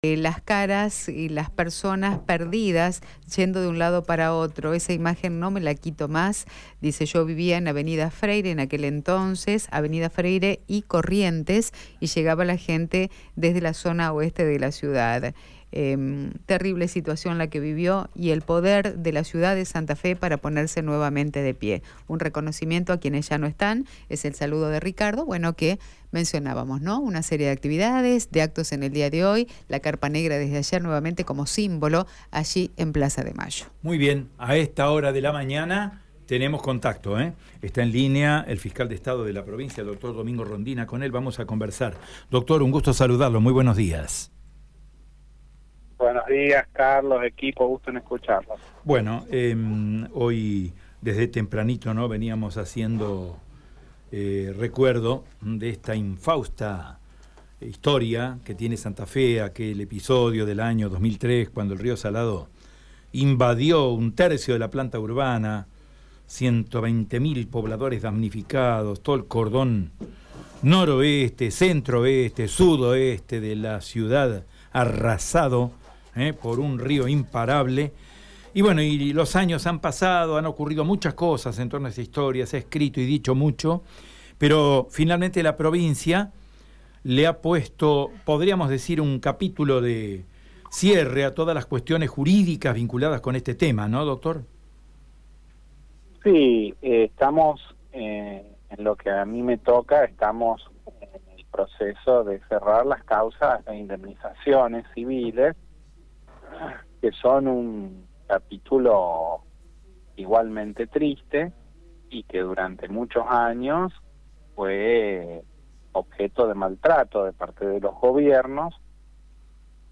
Escucha la palabra de Domingo Rondina en Radio EME: